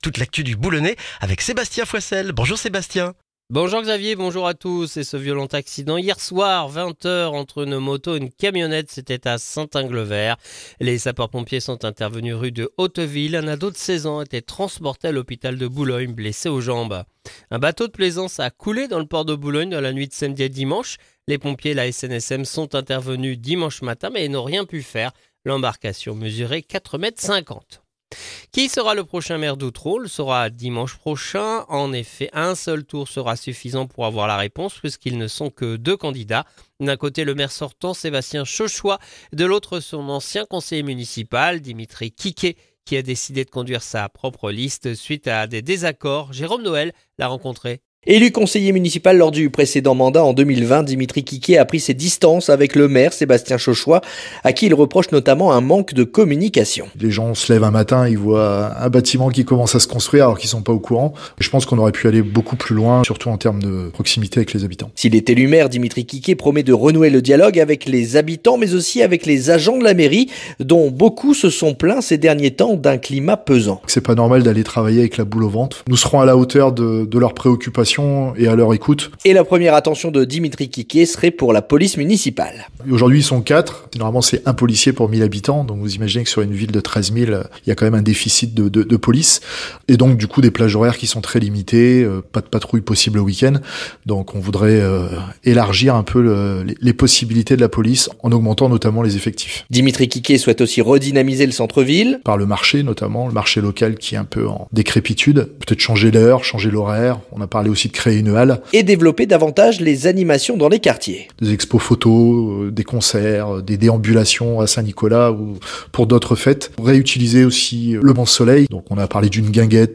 Le journal du lundi 9 mars dans le boulonnais